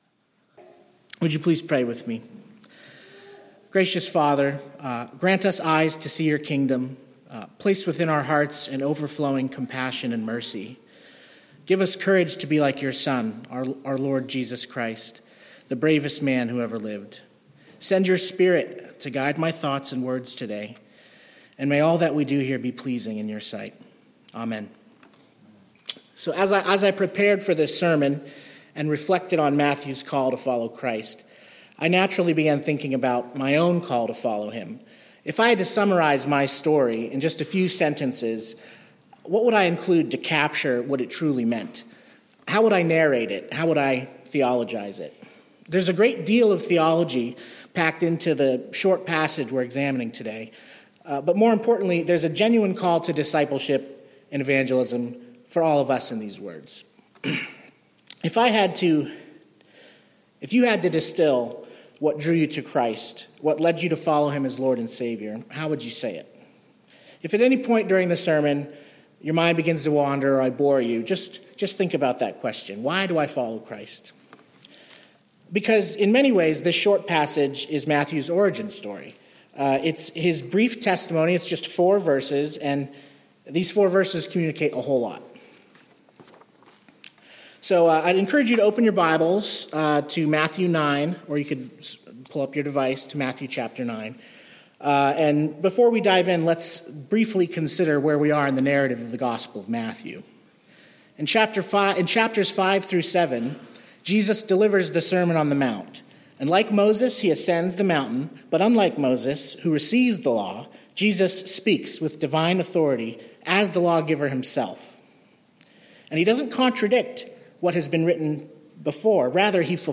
Third Sunday in Lent